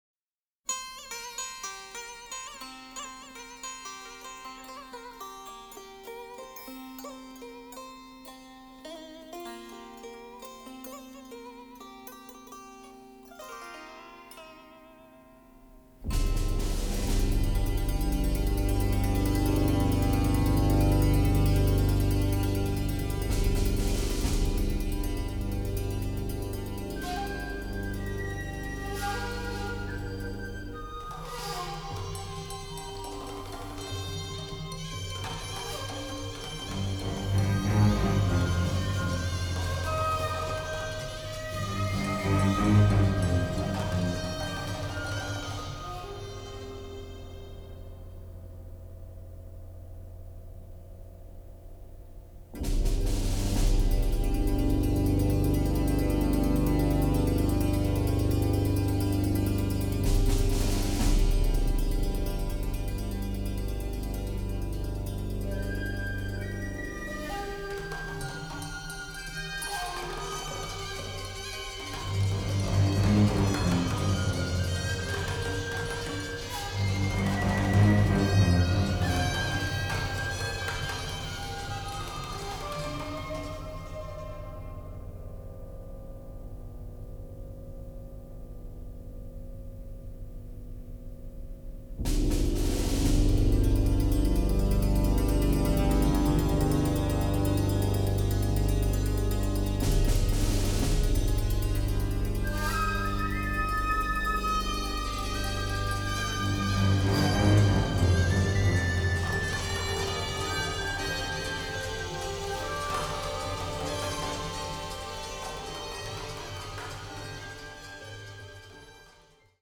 classic war score